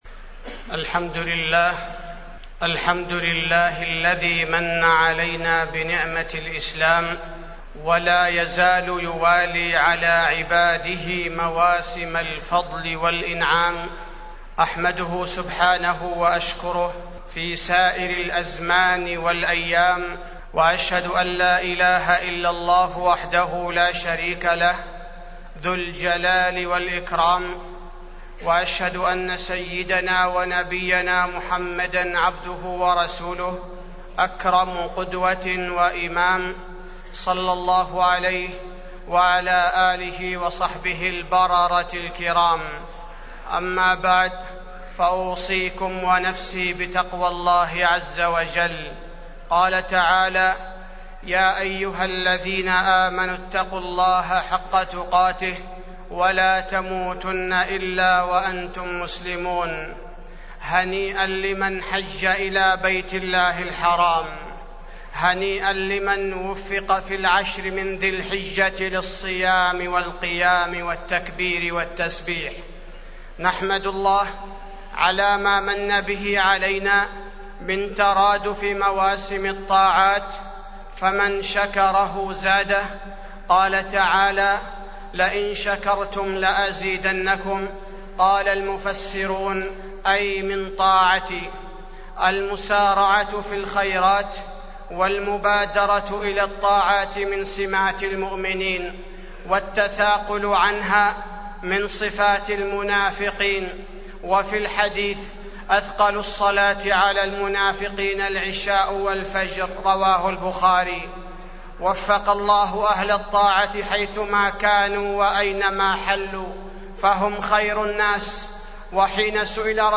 تاريخ النشر ١٥ ذو الحجة ١٤٢٧ هـ المكان: المسجد النبوي الشيخ: فضيلة الشيخ عبدالباري الثبيتي فضيلة الشيخ عبدالباري الثبيتي وصايا للحجاج